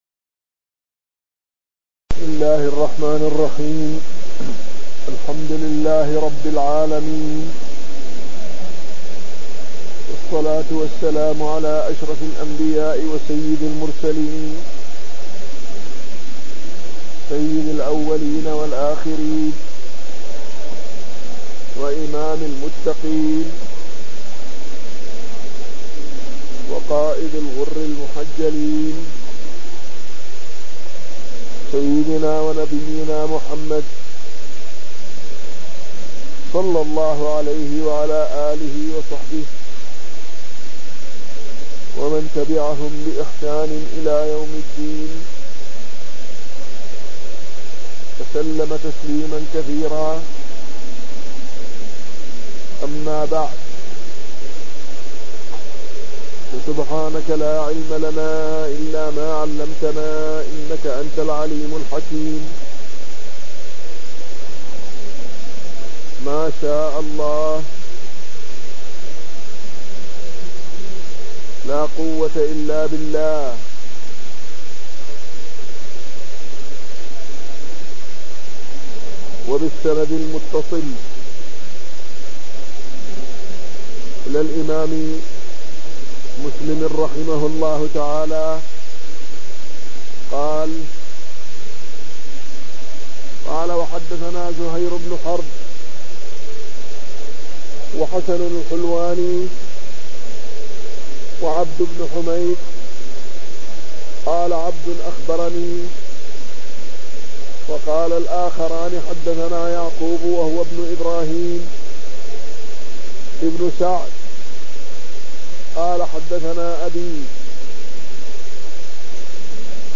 تاريخ النشر ٢٠ ربيع الأول ١٤٣٢ هـ المكان: المسجد النبوي الشيخ